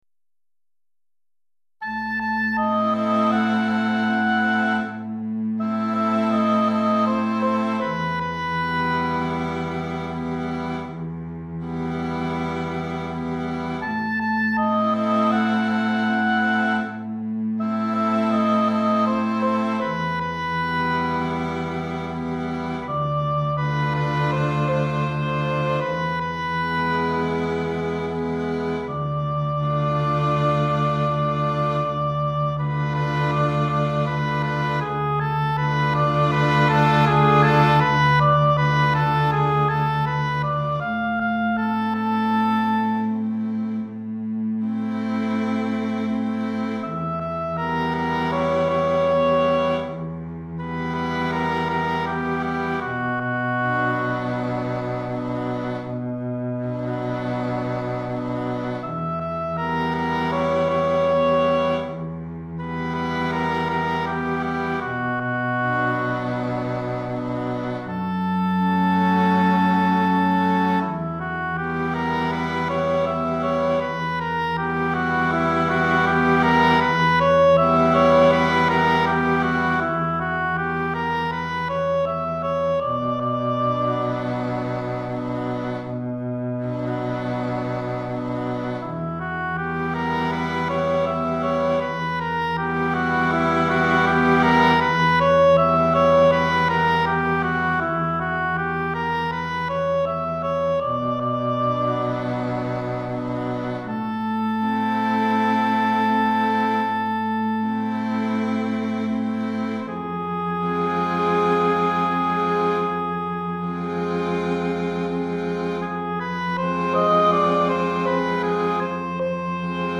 Hautbois 2 Violons 1 Alto 1 Violoncelle